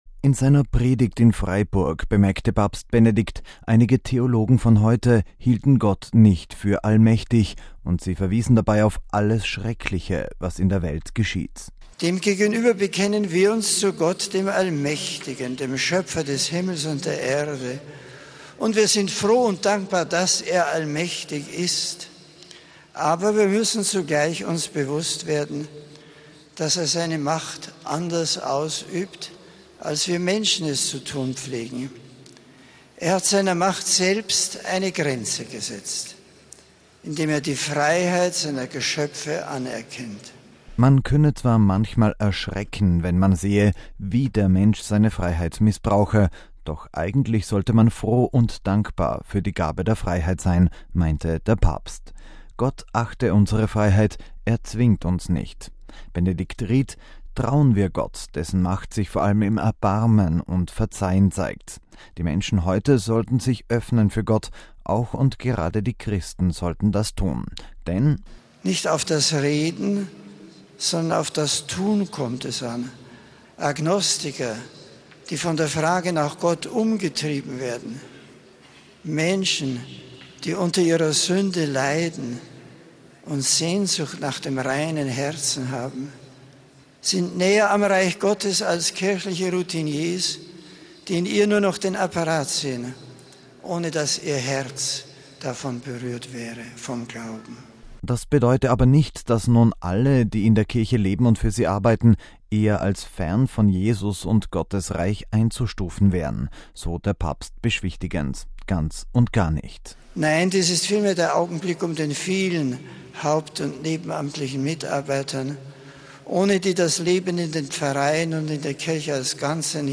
Am letzten Tag seines viertägigen Besuchs in Deutschland hat der Papst an diesem Sonntag die Heilige Messe am Flughafengelände in Freiburg gefeiert.
Musikalisch hat ein Chor, bestehend aus 450 Sängerinnen und Sängern, die Messe begleitet.